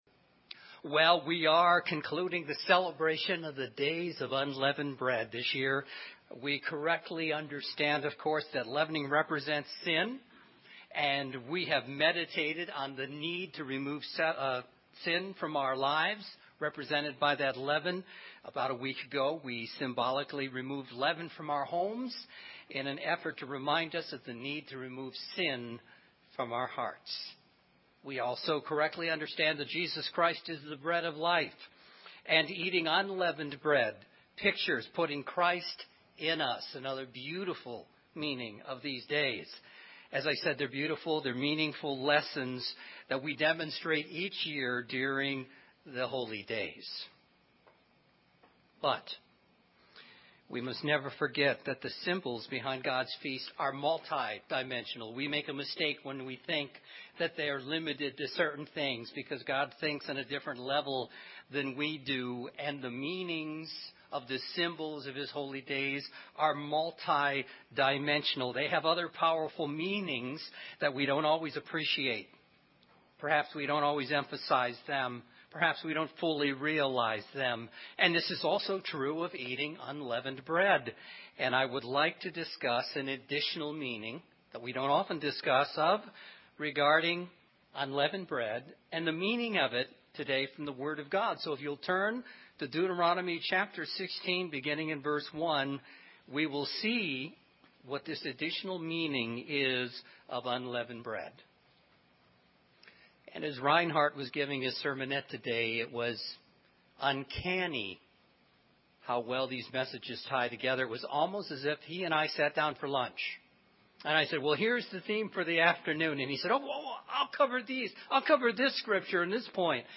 This is true of eating unleavened bread and I would like to discuss an additional meaning of it today from the Word of God in this sermon on the 7th Day of Unleavened Bread.